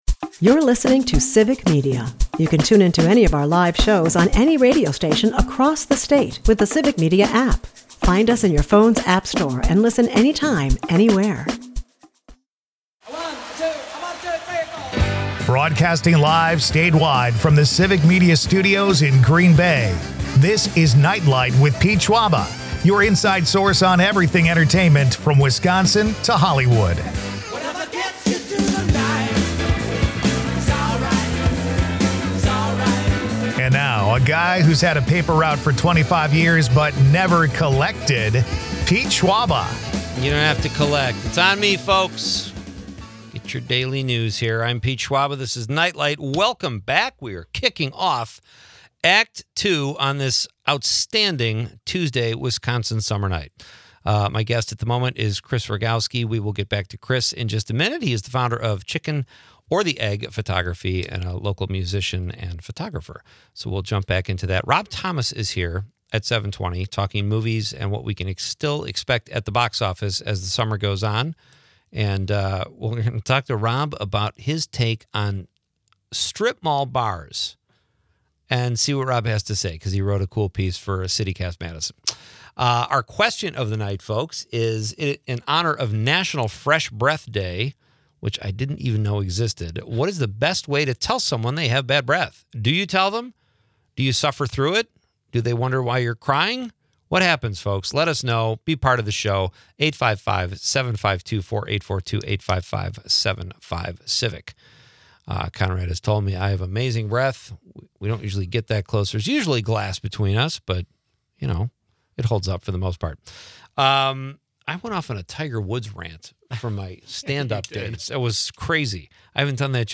The conversation humorously touches on National Fresh Breath Day, with listeners chiming in on how to handle bad breath situations.